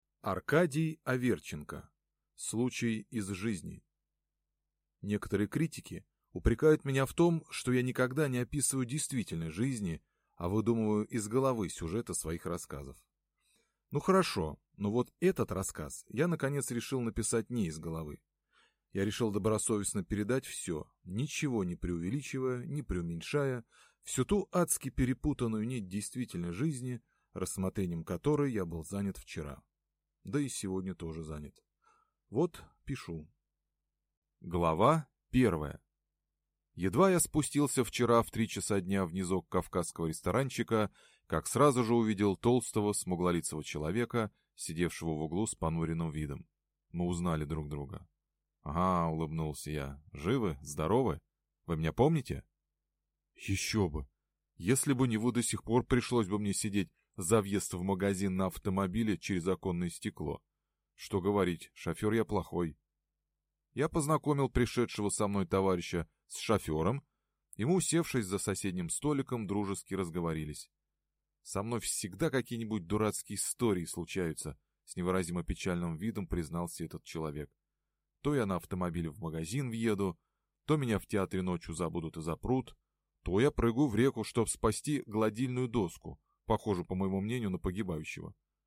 Аудиокнига Случай из жизни | Библиотека аудиокниг